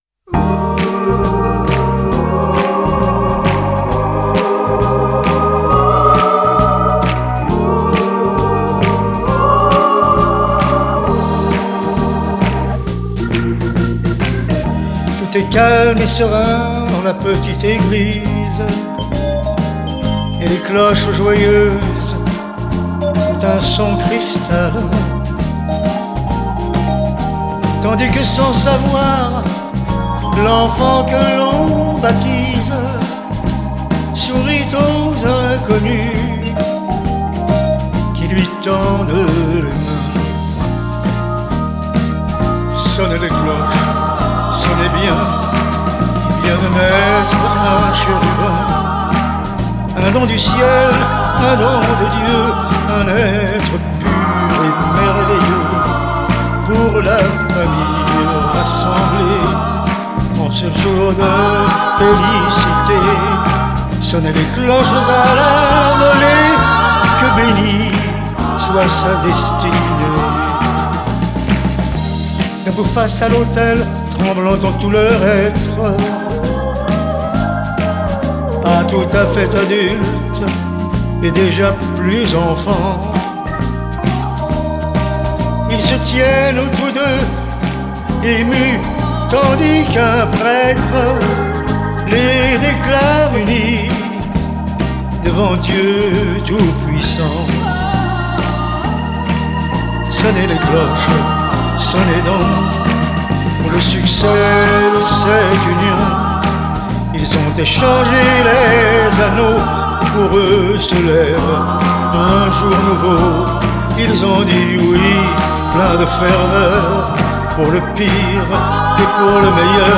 Genre: Chanson